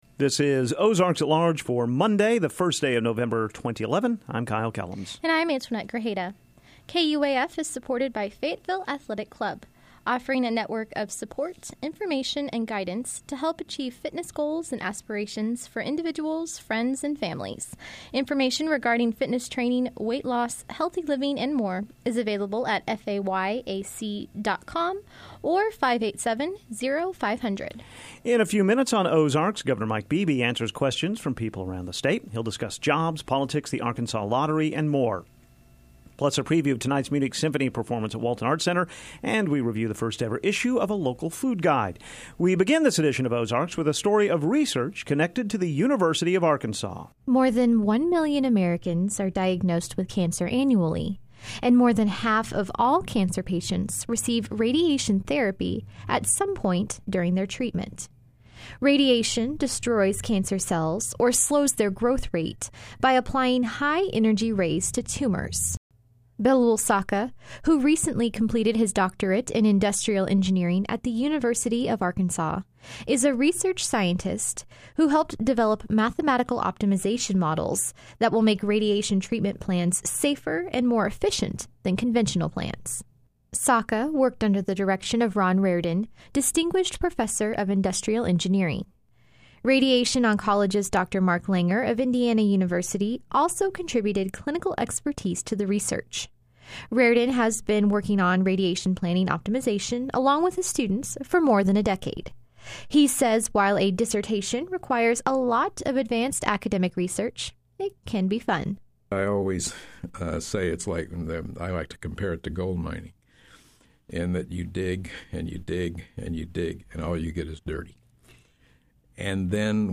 Audio: 11-1-11.mp3 On this edition of Ozarks at Large, Governor Mike Beebe answers questions from people around the state, and a preview of tonight’s Munich Symphony Orchestra performance at Walton Arts Center. Also on the show today, details on radiation-related research conducted by University of Arkansas engineering researchers.